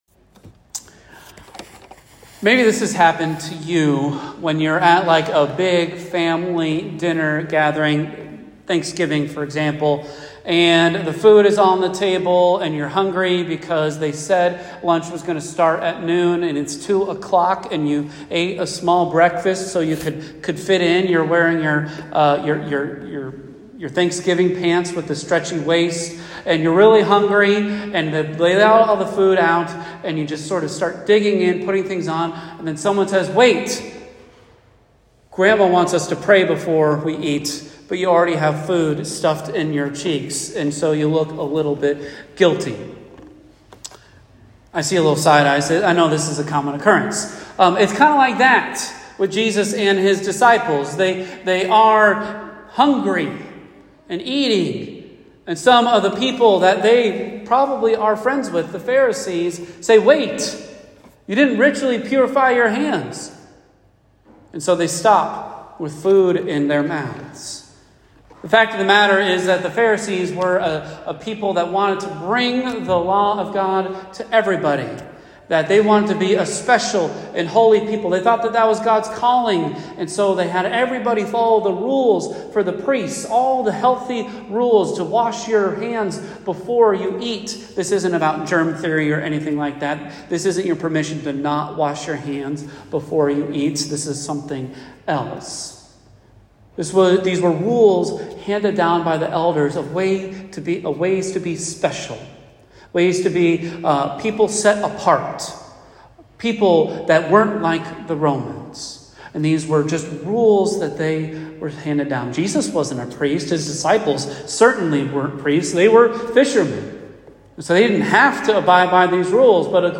Venue: First Christian Church Scripture: Mark 7:1-8